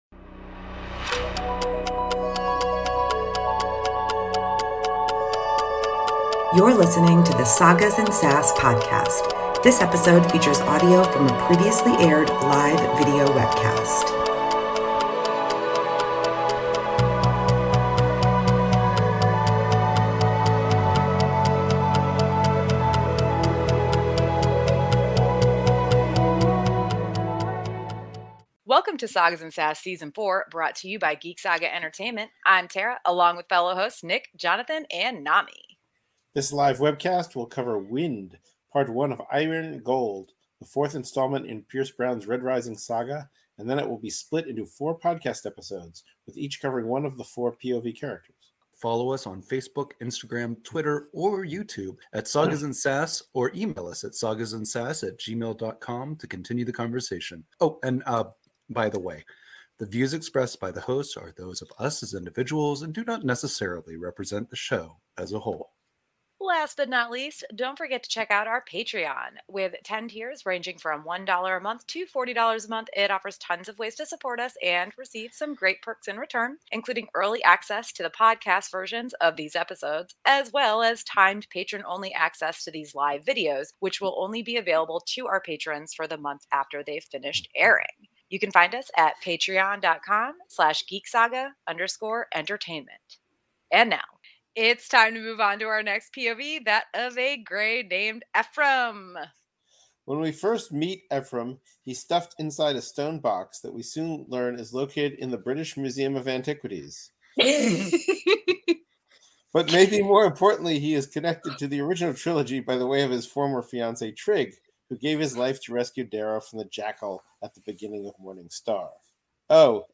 Episode 63 of the Sagas & Sass Podcast originally aired as a live webcast on March 8, 2023.
While there are likely to be some [very minor!] spoilers mistakenly revealed in our live webcasts, anything that isn’t specifically contained in Ephraim’s POV chapters from Iron Gold Part 1 “WIND” has been edited out of the podcast version.